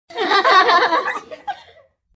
Children Laughing Sound Effect
Small group of children laughing and giggling. Short bursts of happy kids’ laughter in a cheerful, positive atmosphere. Child giggles, joyful play, and fun sound effect.
Children-laughing-sound-effect.mp3